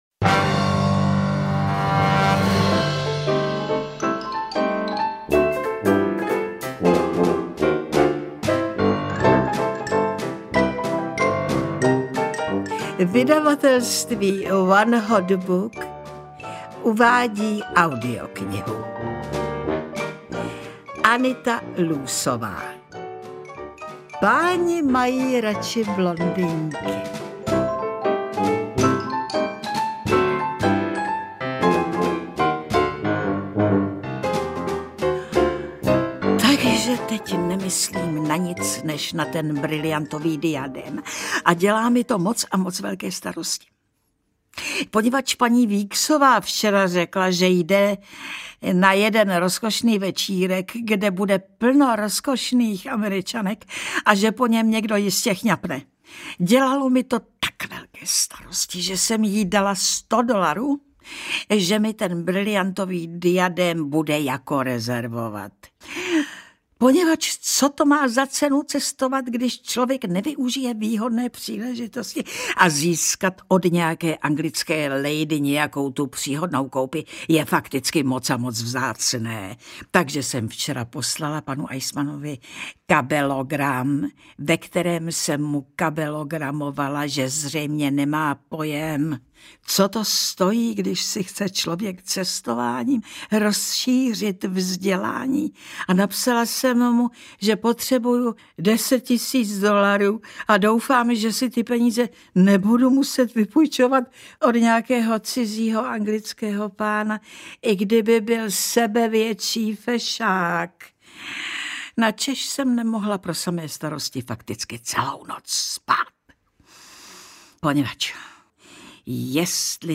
Audiobook
Read: Alena Vránová